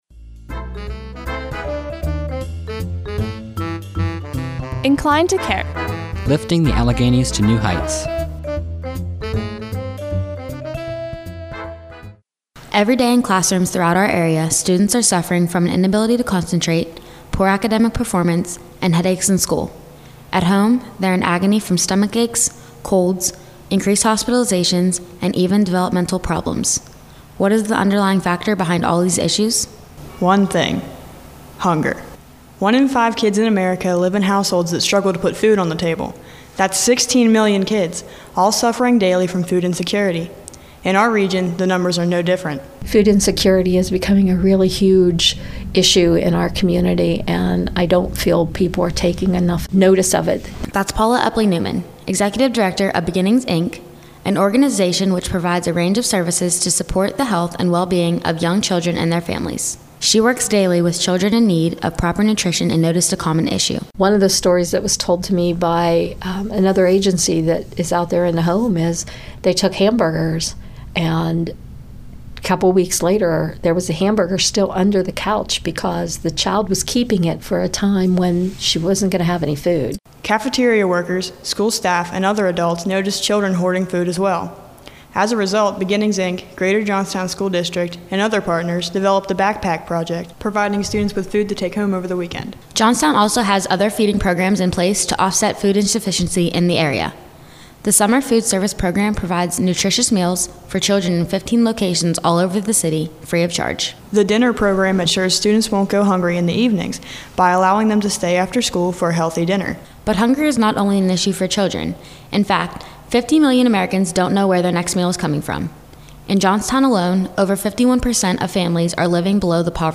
After initial training at SLB Radio Productions in Pittsburgh, students were provided with microphones, digital recorders, editing software and ongoing coaching to create these succinct broadcast features.
RADIO DOCUMENTARIES